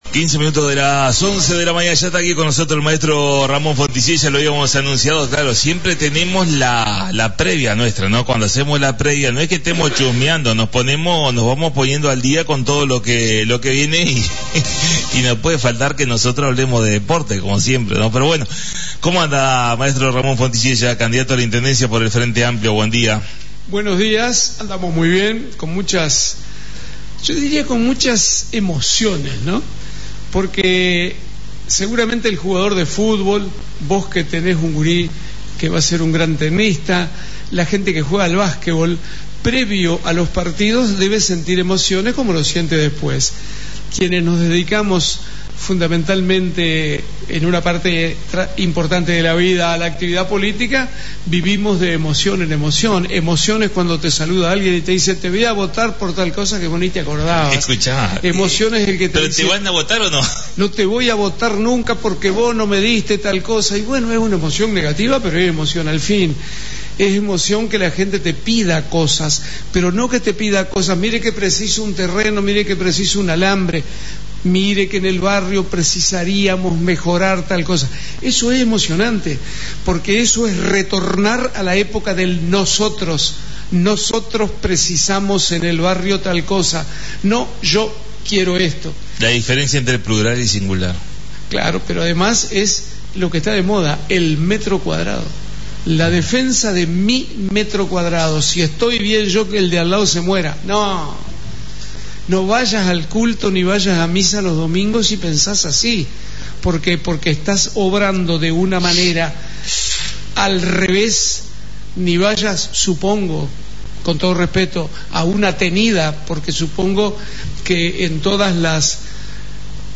La visita a la Radio del Maestro Ramón Fonticiella Candidato a la Intendencia de Salto por el Frente Amplio